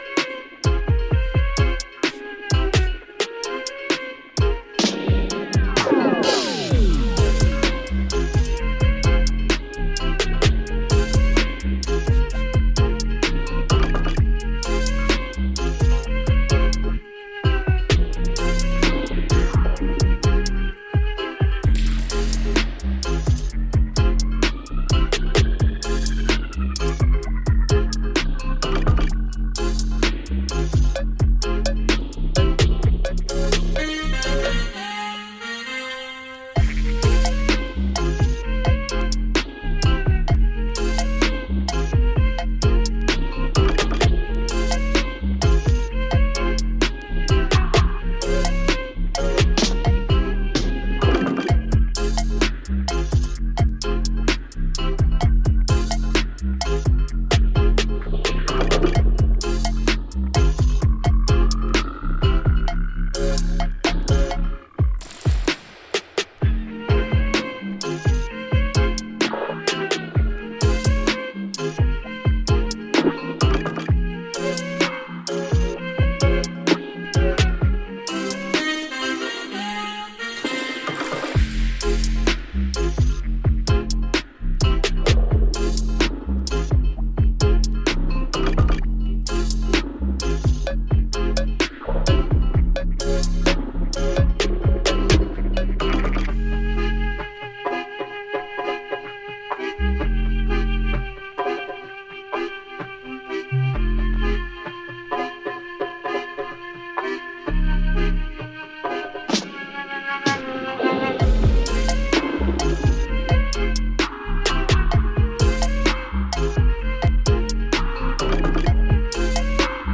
Reggae/Dub